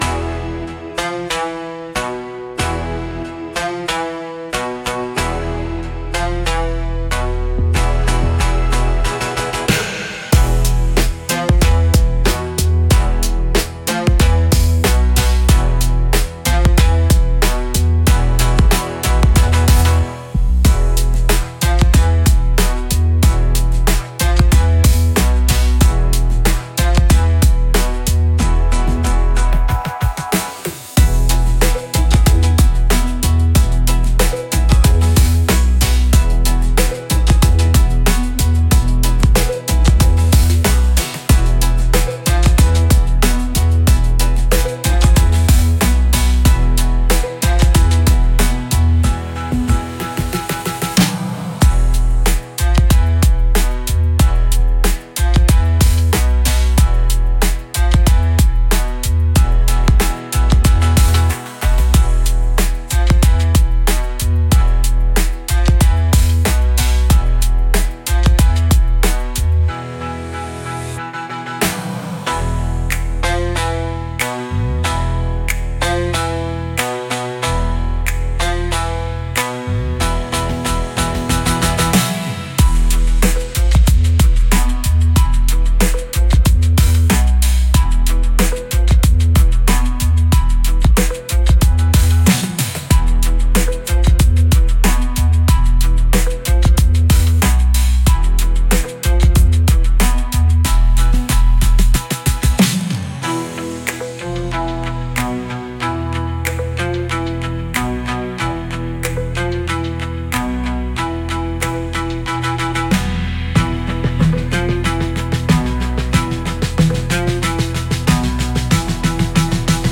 Instrumental - Submerge - 2.37 mins